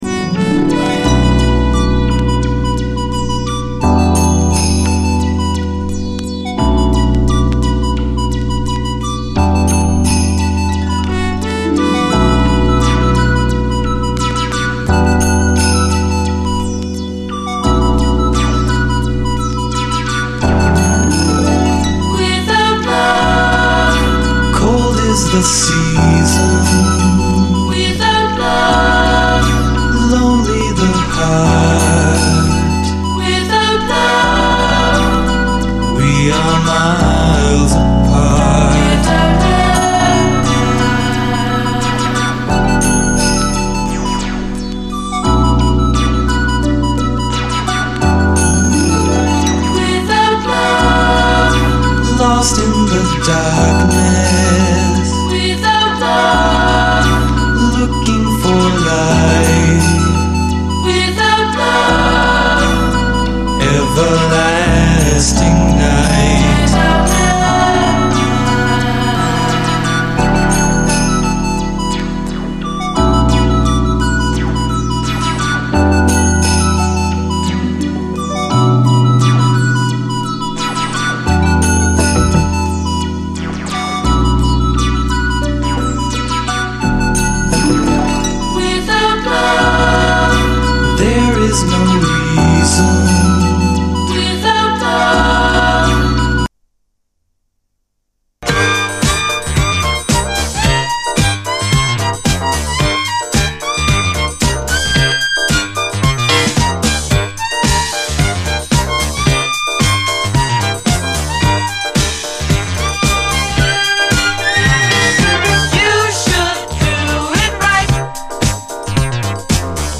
SOUL, 70's～ SOUL, DISCO, 7INCH